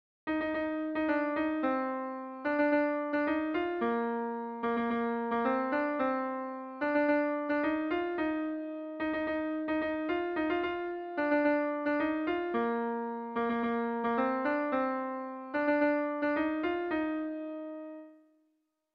Sentimenduzkoa
ABA2B